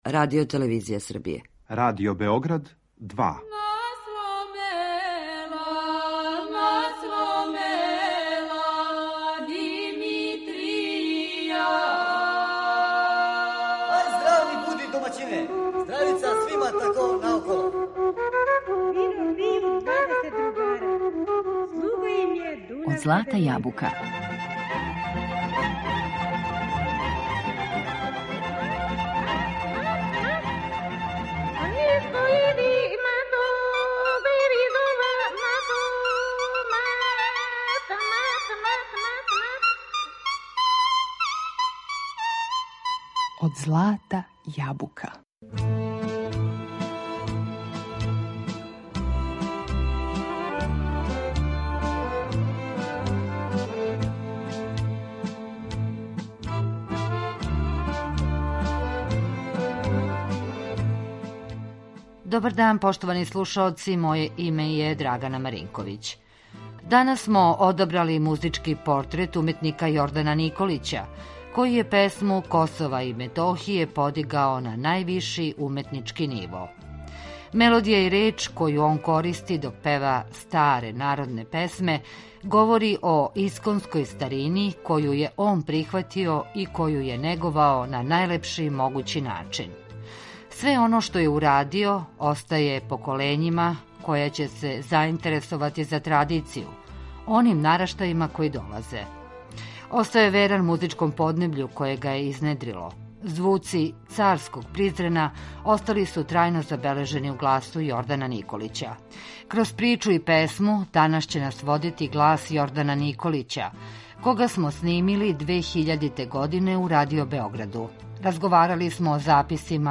Мелодије и речи које он користи док пева старе народне песме говоре о исконској старини коју је прихватио и коју је неговао на најлепши начин.